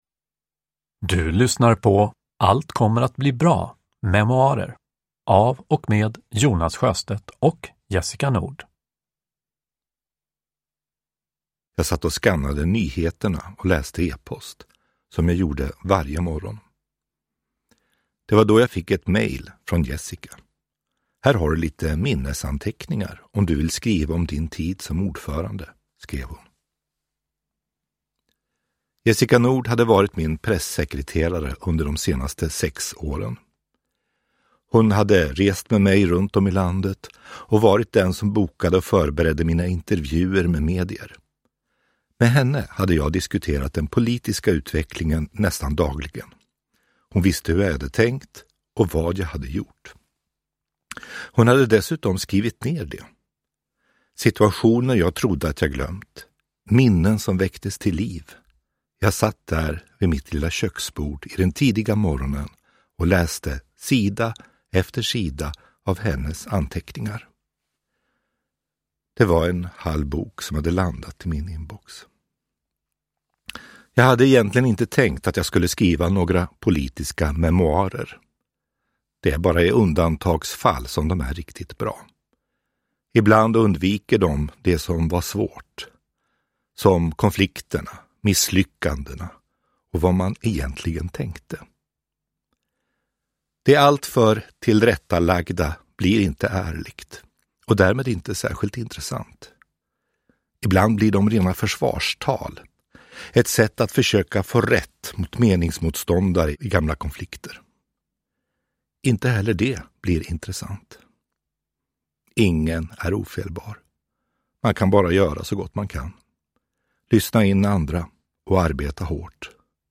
Allt kommer att bli bra : memoarer – Ljudbok – Laddas ner
Uppläsare: Jonas Sjöstedt